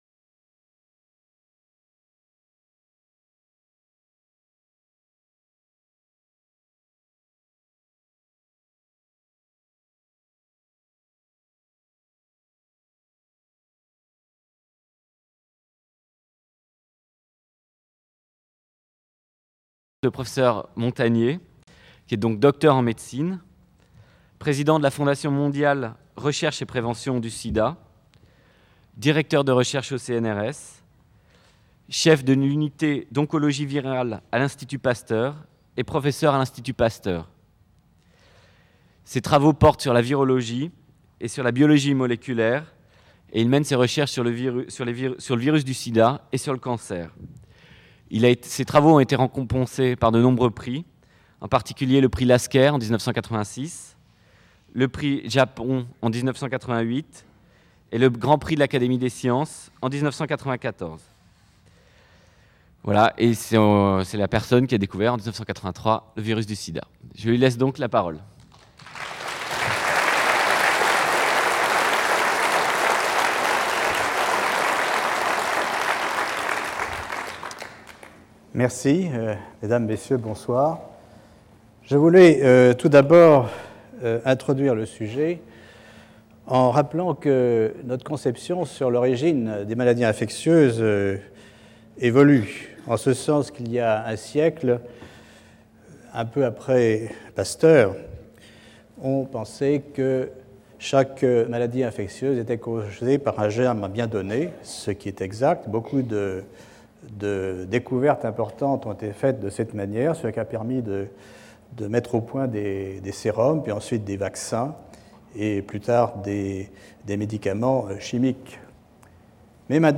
Cette conférence fera la bilan de près de 20 ans de recherches sur les rétrovirus du SIDA, en discutant leur origine, les mécanismes par lesquels ils induisent une maladie mortelle chez l'homme, les perspectives apportées par les nouvelles thérapeutiques, les espoirs d'un vaccin. On évoquera également les autres maladies où d'autres rétrovirus sont impliqués, ou soupçonnés de l'être.